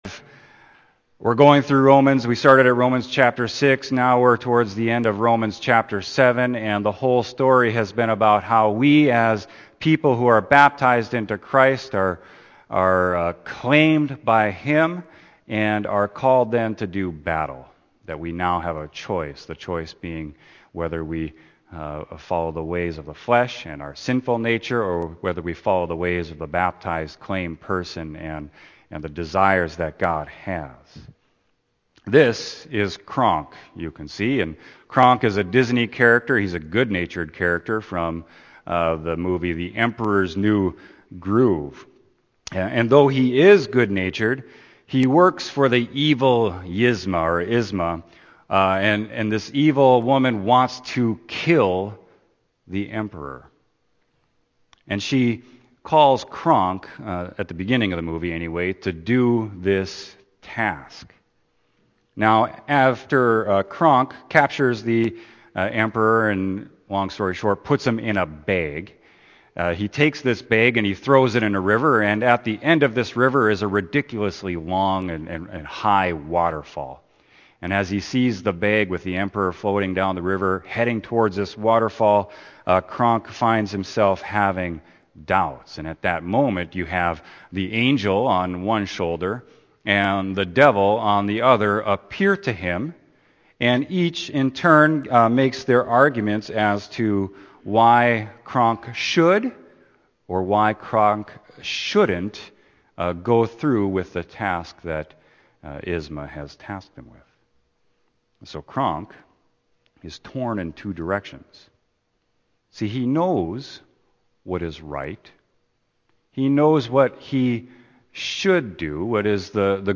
Sermon: Romans 7.15-25a